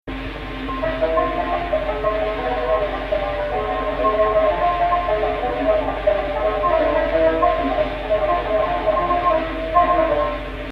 Type: Suznak ascending